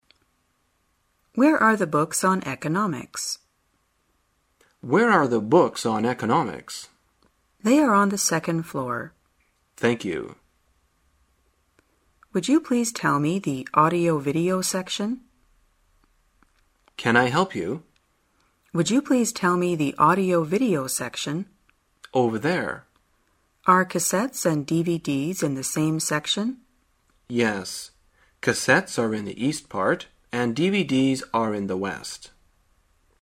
在线英语听力室生活口语天天说 第35期:怎样询问信息的听力文件下载,《生活口语天天说》栏目将日常生活中最常用到的口语句型进行收集和重点讲解。真人发音配字幕帮助英语爱好者们练习听力并进行口语跟读。